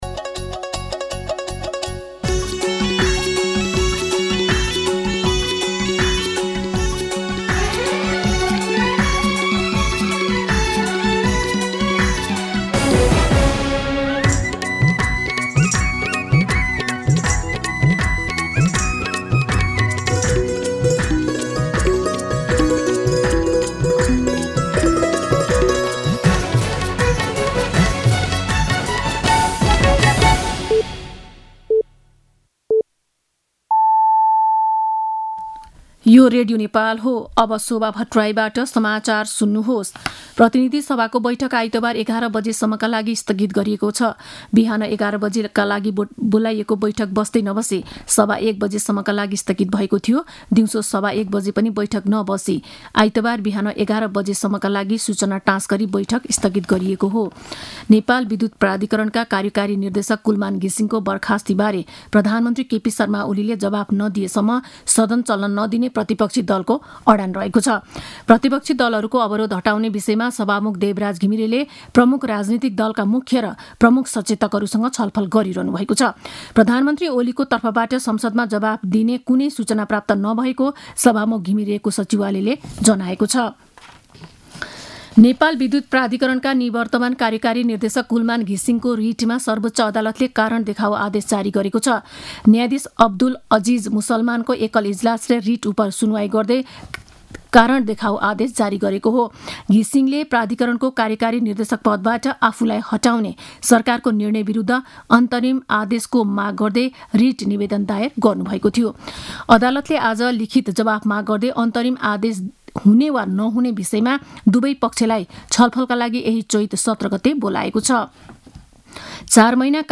दिउँसो ४ बजेको नेपाली समाचार : १५ चैत , २०८१
4pm-news-1.mp3